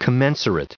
155_commensurate.ogg